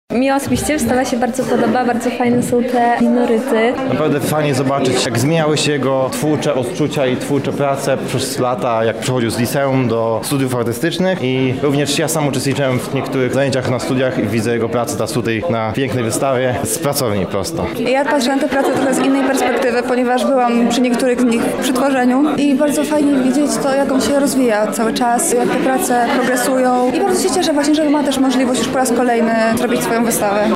O przemianach w stylu artysty rozmawialiśmy również z odwiedzającymi wystawę:
Relacja z wystawy „Proces”
Relacja-widzow_01.mp3